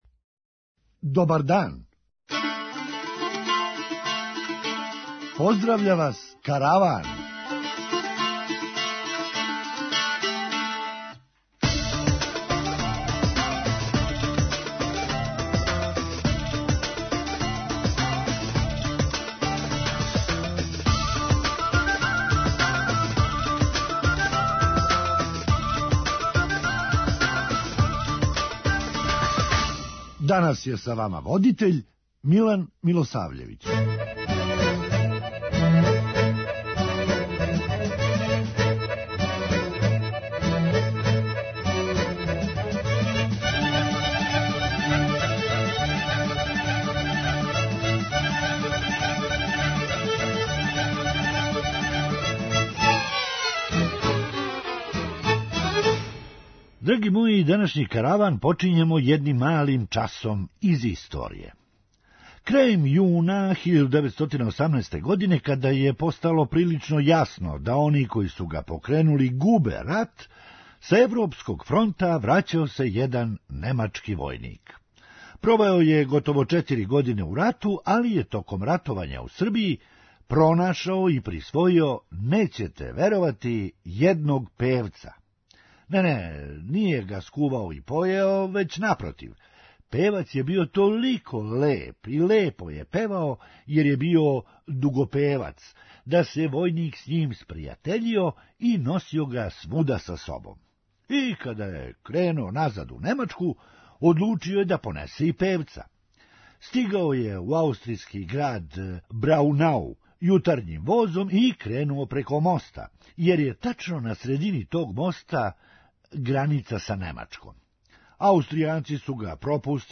Хумористичка емисија
- завапио је мој комшија због тога - Па ни кокошке не носе јаја недељом!'' преузми : 9.20 MB Караван Autor: Забавна редакција Радио Бeограда 1 Караван се креће ка својој дестинацији већ више од 50 година, увек добро натоварен актуелним хумором и изворним народним песмама.